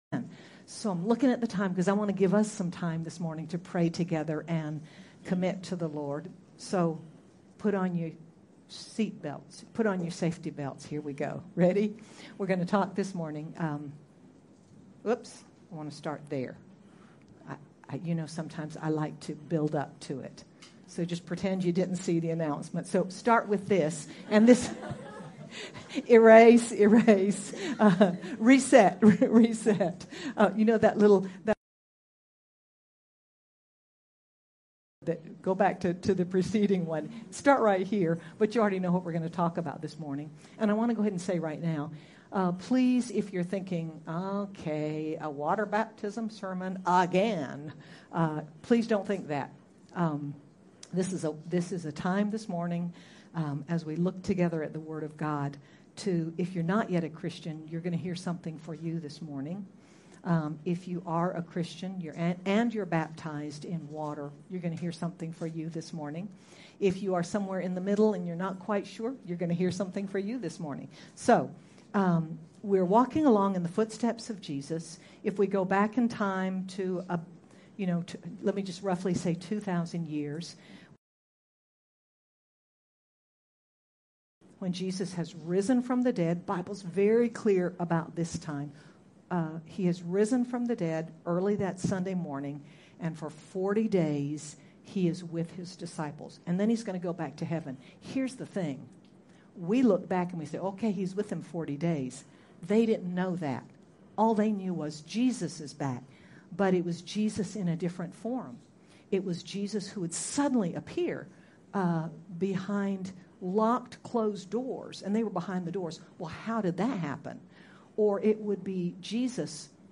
In this message we take a look at what God says about baptism and why it’s important for every believer. Sermon by